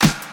• HQ Dry Snare Sound D# Key 56.wav
Royality free snare drum tuned to the D# note. Loudest frequency: 1971Hz
hq-dry-snare-sound-d-sharp-key-56-nN2.wav